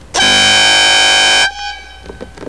Hella Twin-Tone Compressor Air Horn Kit
Click to hear a single blare of the Hellas
Replaces stock electric horns with two air compressor powered trumpet horns for a more appealing sound.
Sounds like a Porsche or Italian sports car horn.